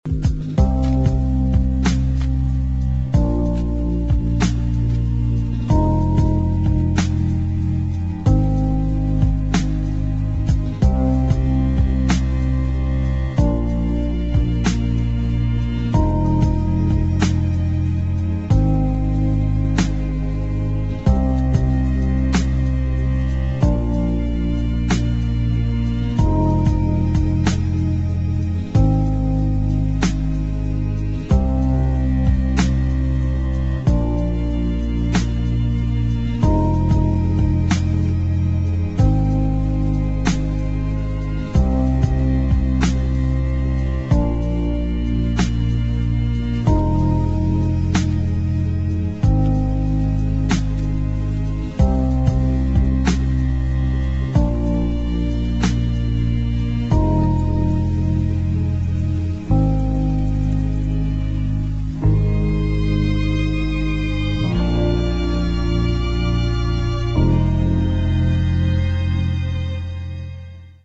DOWNBEAT / JAZZ